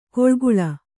♪ koḷguḷa